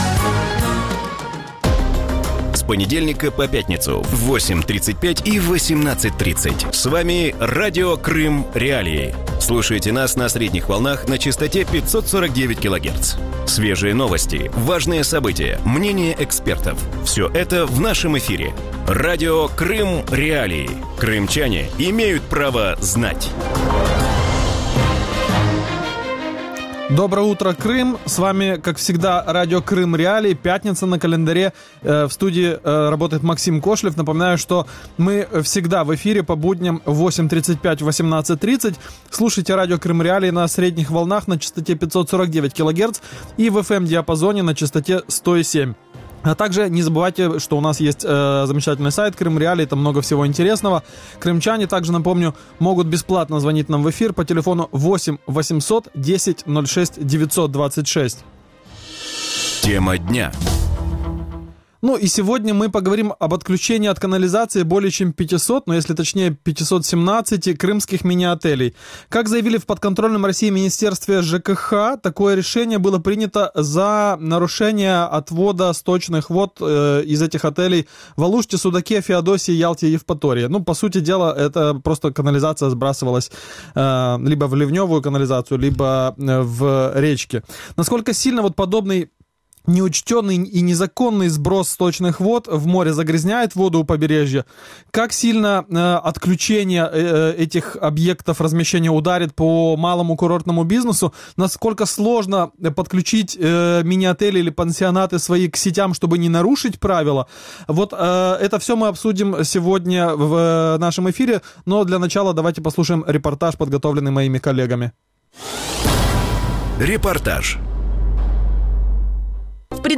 Утром в эфире Радио Крым.Реалии говорят об отключении от канализации 500 крымских мини-отелей.